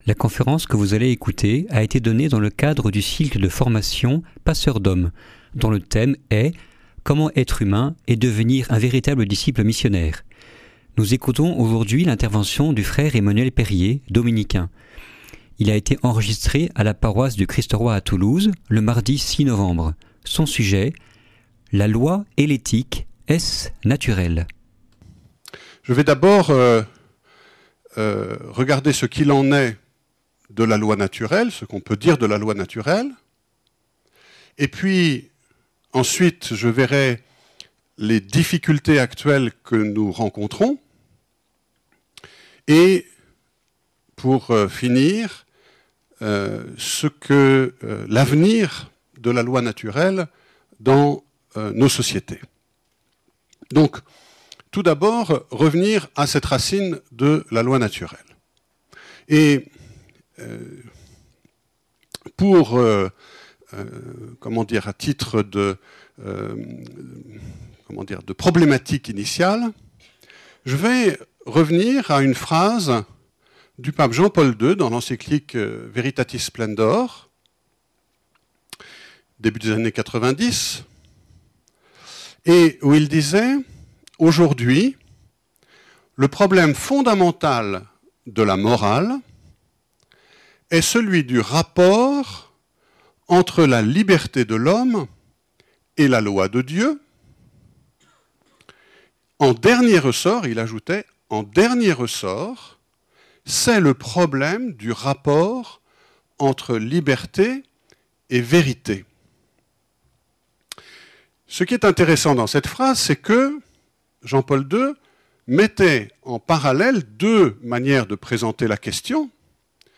conference1846_etre_droit_-_la_loi_et_l_ethique.mp3